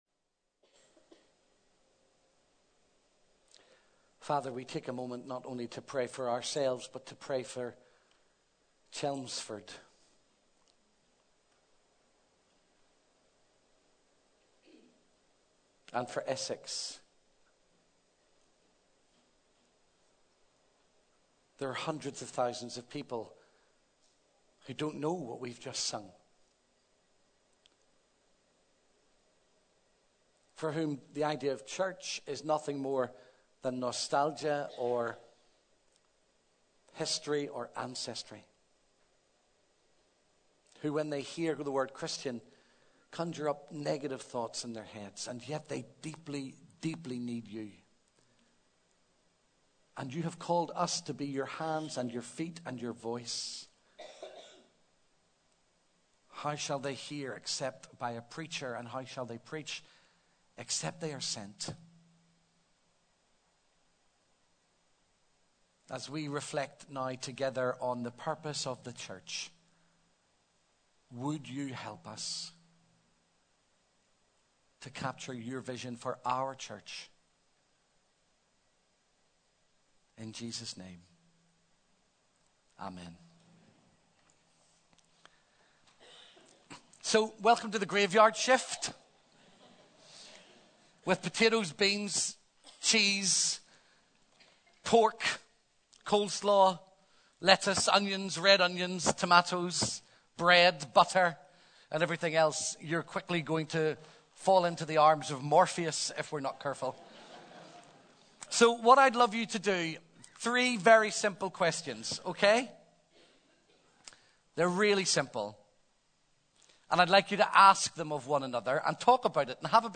A sermon preached on 23rd January, 2016, as part of our Big Day In series.